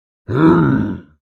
Zombie Grunt Single
Zombie Grunt Single is a free horror sound effect available for download in MP3 format.
Zombie Grunt Single.mp3